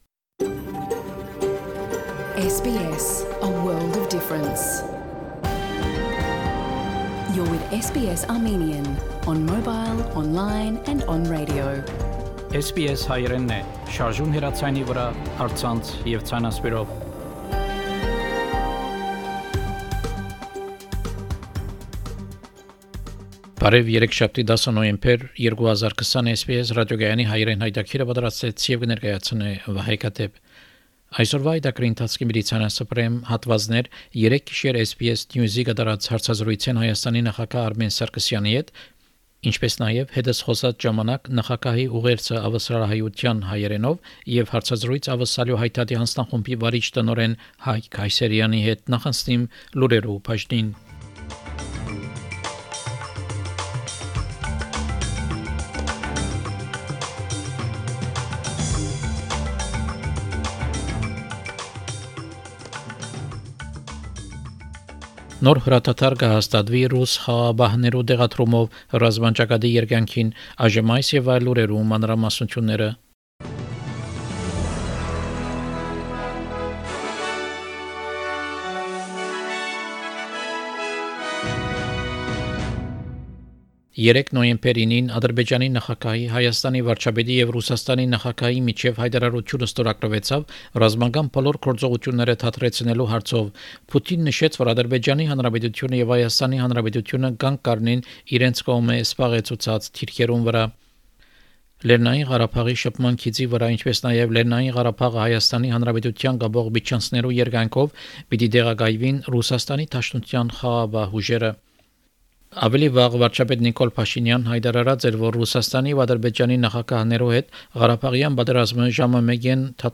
SBS Armenian news bulletin – 10 November 2020
SBS Armenian news bulletin from 10 November 2020 program.